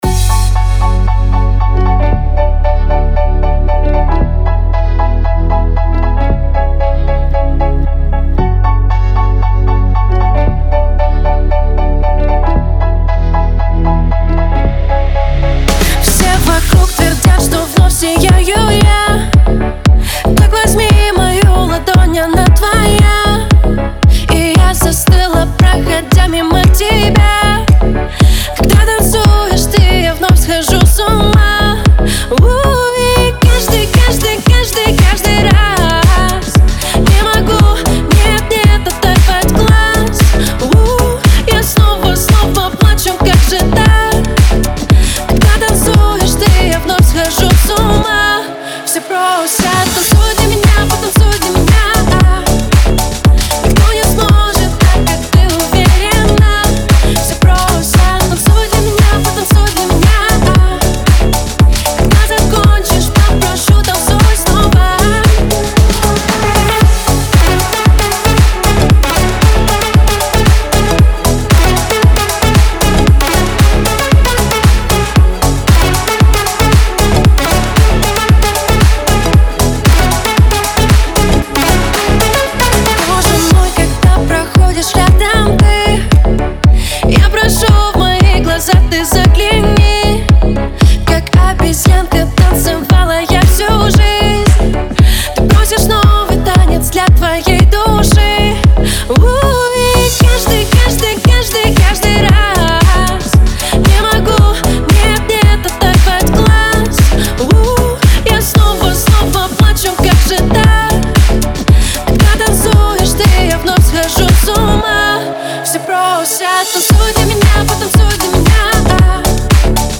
• Жанр: New Rus / Русские песни